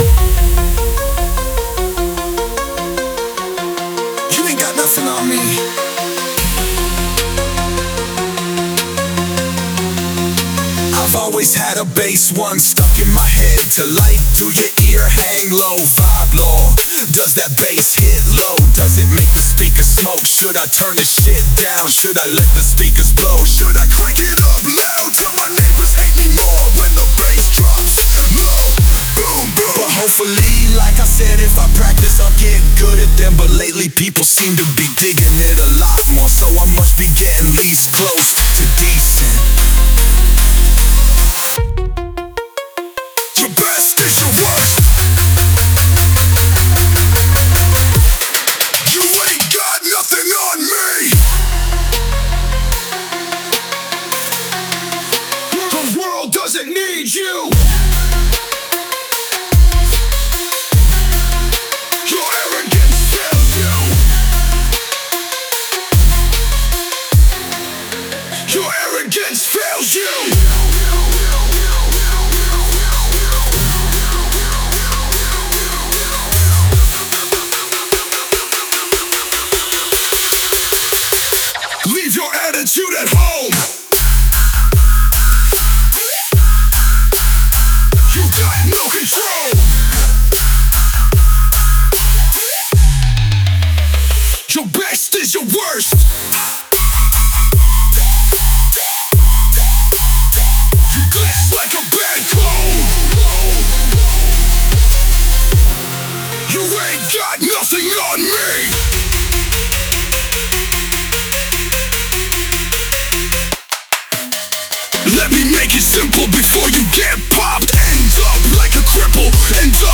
Genre: Hip-Hop / Rap